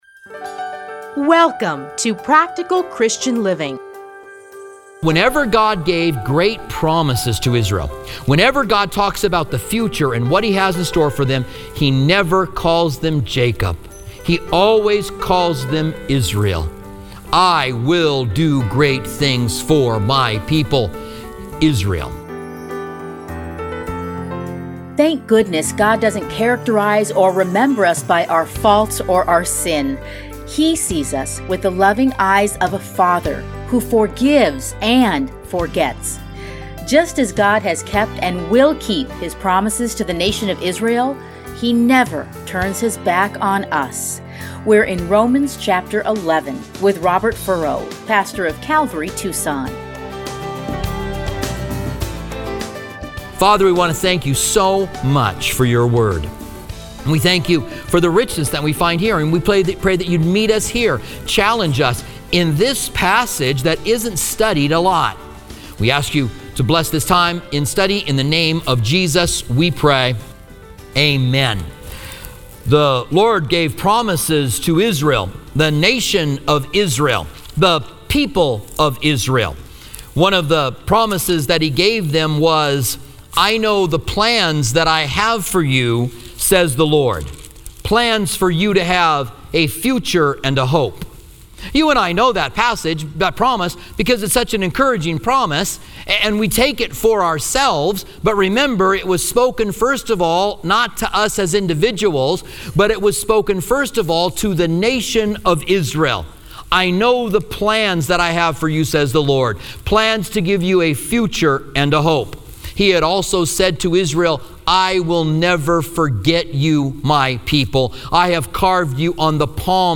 teachings are edited into 30-minute radio programs